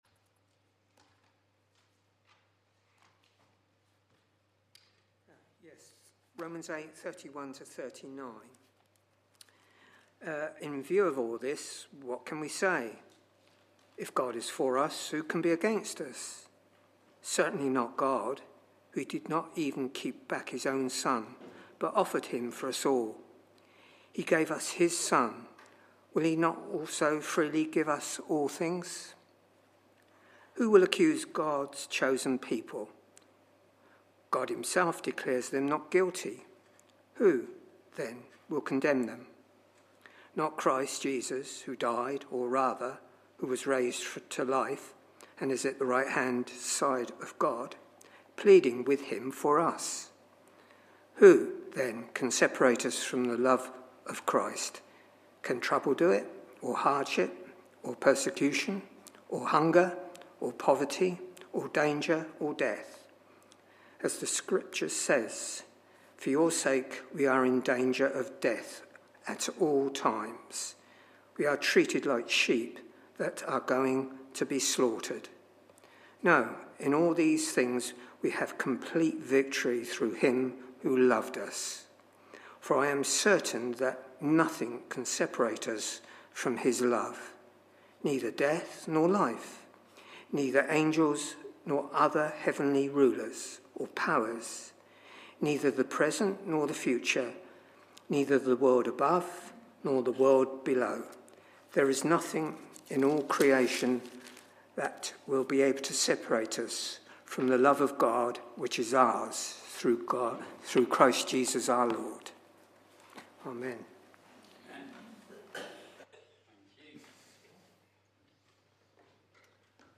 A talk from the series "Traditional Service."
Messages from our Traditional Services